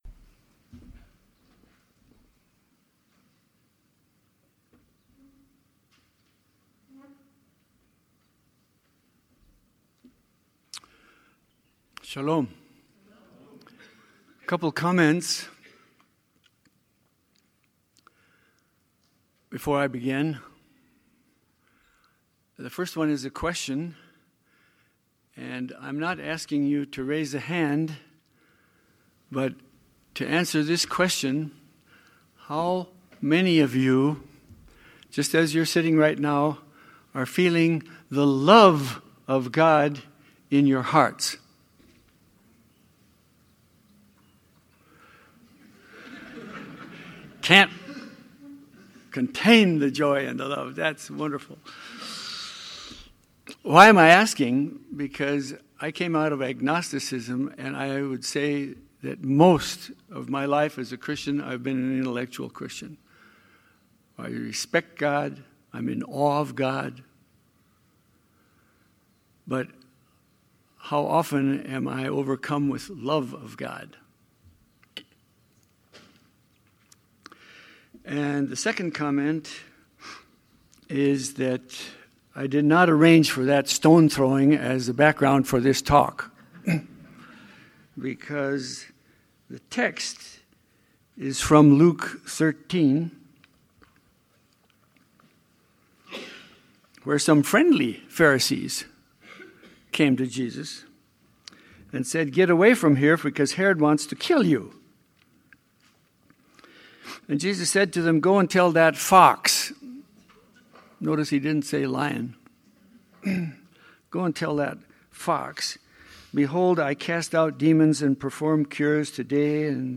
Narkis St. Congregation Lenten Sermon Series